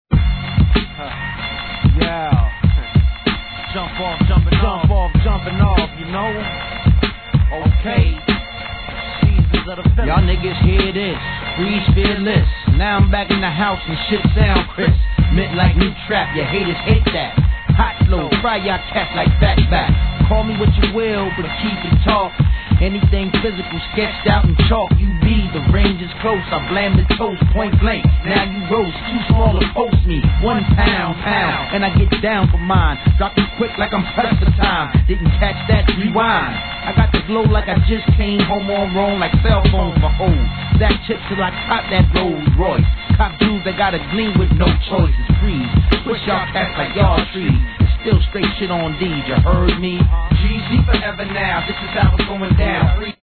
HIP HOP/R&B
2000年、アンダーグランド!